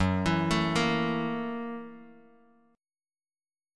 F#7#9 chord